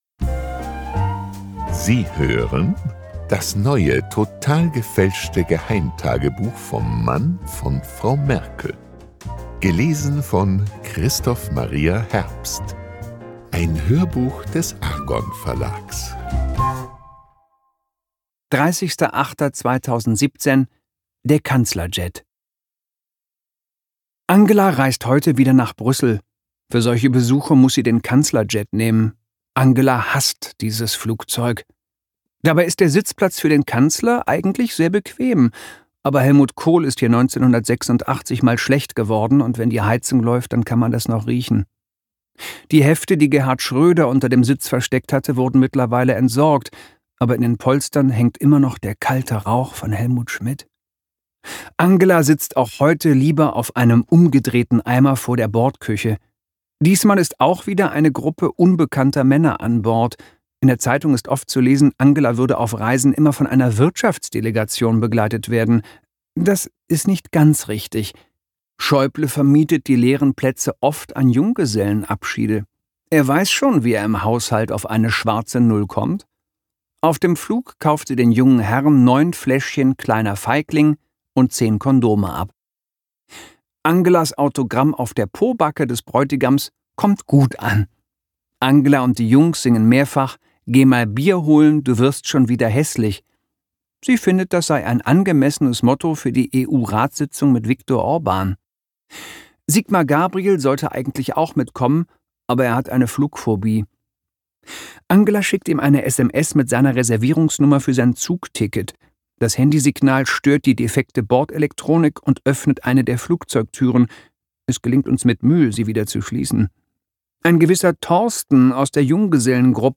Christoph Maria Herbst (Sprecher)
Themenwelt Literatur Comic / Humor / Manga Humor / Satire